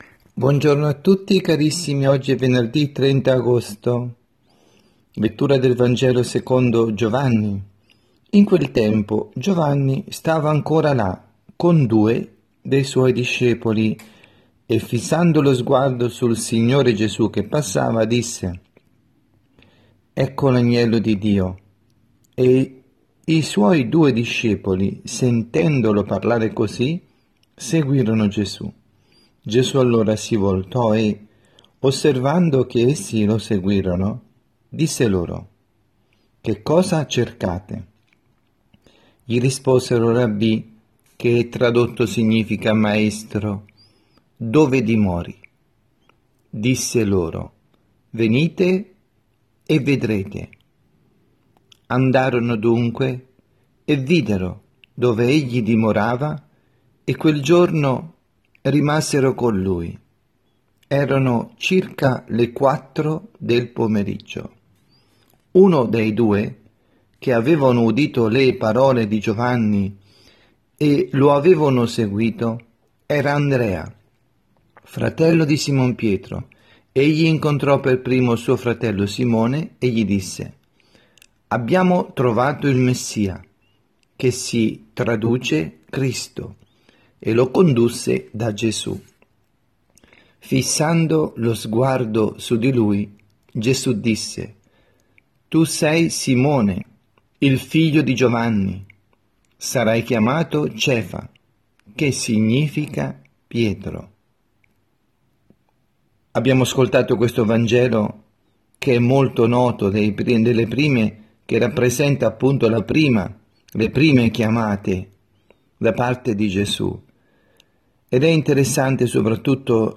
avvisi, Omelie
2019-08-30_Venerdi_pMG_Omelia_dalla_Casa_di_Riposo_S_Marta_Milano